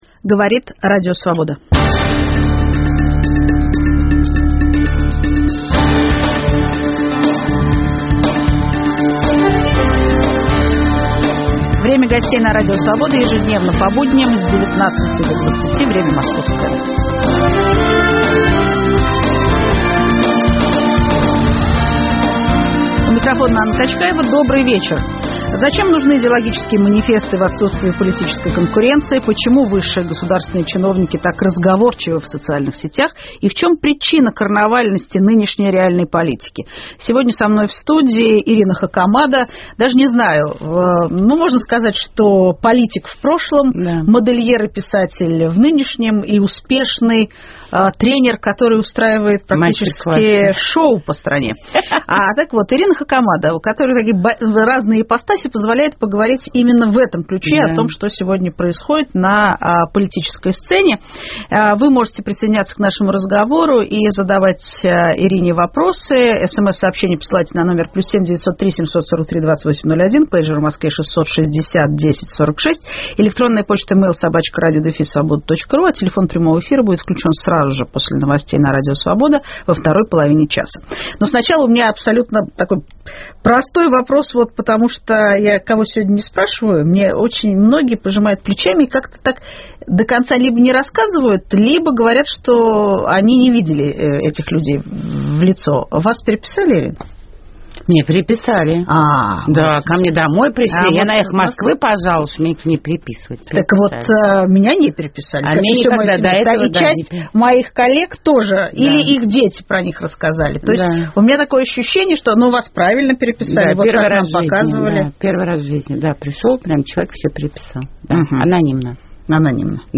Зачем нужны идеологические "манифесты" в отсутствии политической конкуренции? Почему высшие государственные чиновники так разговорчивы в социальных сетях? В чем причина "карнавальности" реальной политики? В студии - Ирина Хакамада.